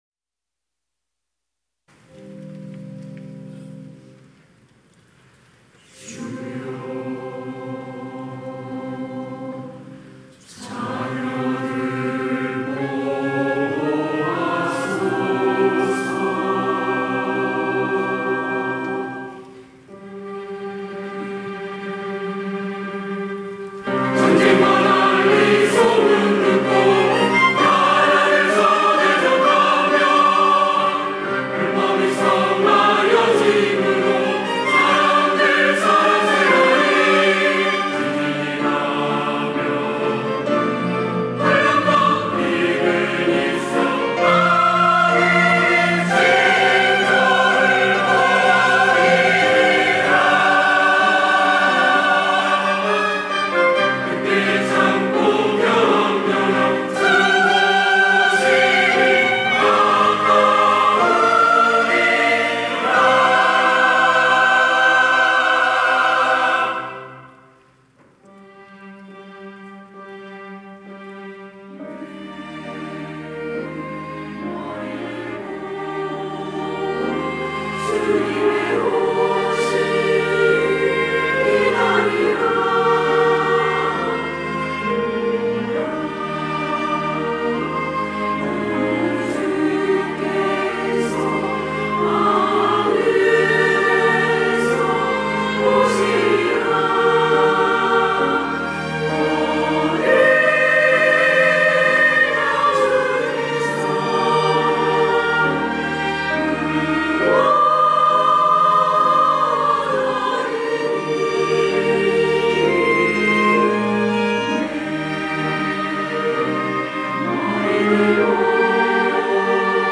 호산나(주일3부) - 보라 주 오시리라
찬양대